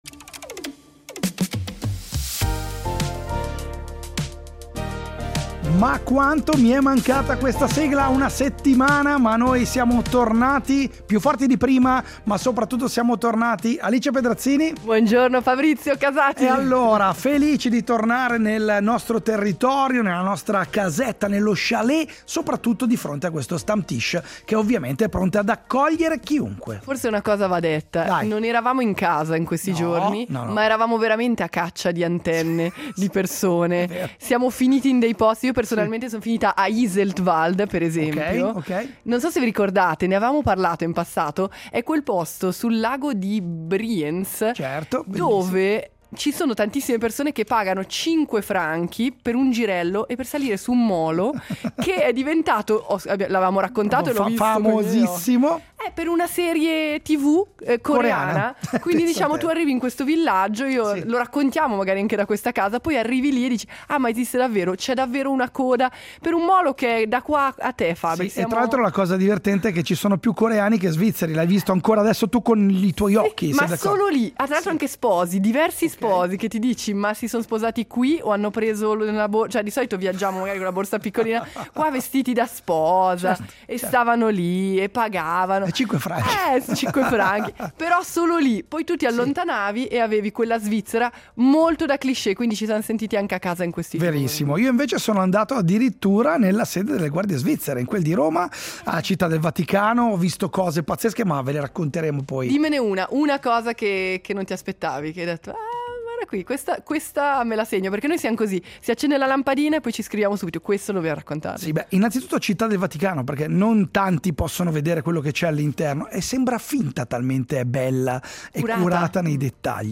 Oggi a “ Casa Svizzera ” siamo partiti dal lago di Bienne, con un collegamento da La Neuveville.